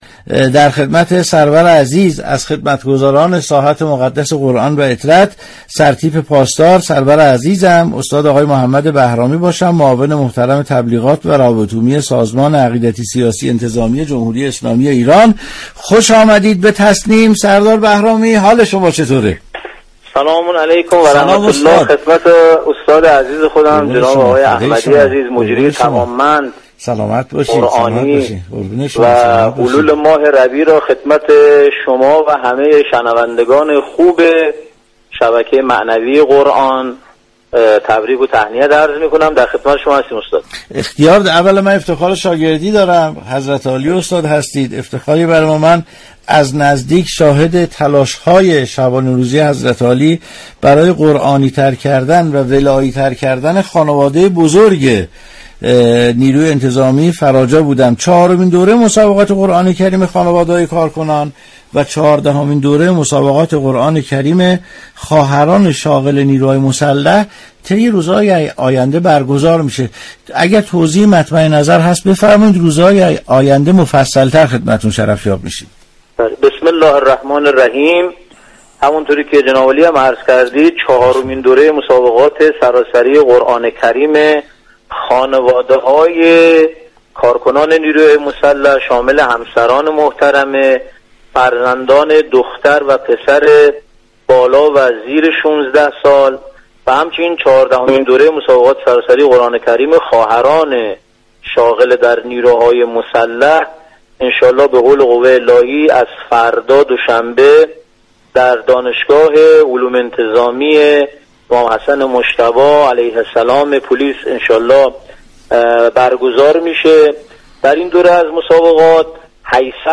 گفتنی است؛ مجله صبحگاهی "تسنیم" با رویكرد اطلاع رسانی همراه با بخش هایی متنوع، شنبه تا پنجشنبه از شبكه ی رادیویی قرآن به صورت زنده تقدیم شنوندگان می شود.